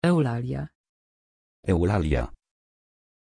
Aussprache von Eulalia
pronunciation-eulalia-pl.mp3